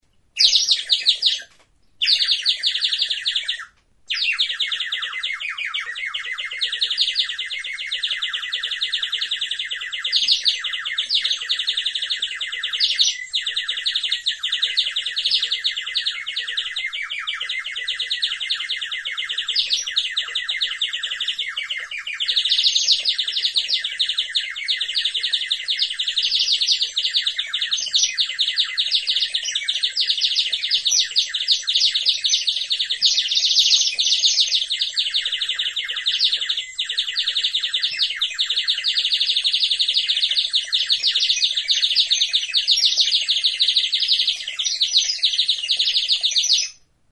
TXIOKA; TXORIA; ur flauta | Soinuenea Herri Musikaren Txokoa
Enregistr� avec cet instrument de musique.
Txori baten antza duen buztinezko ur-flauta da.